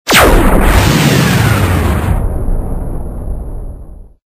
hugeLaser.wav